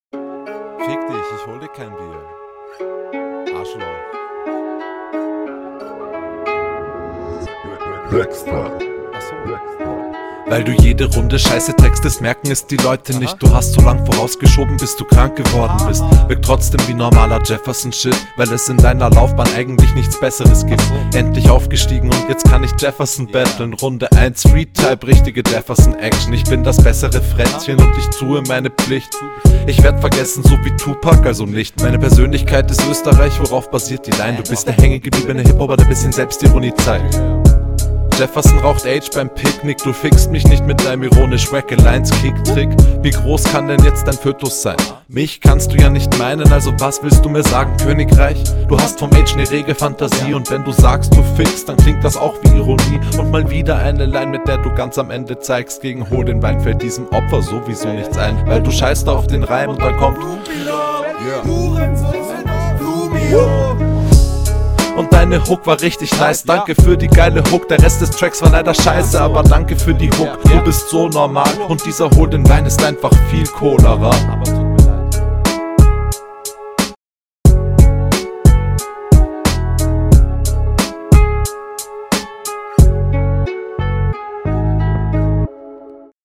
Flow: Guter Flow, interessante Pausen Text: Text ebenfalls sehr interessant, fand den Text aber von …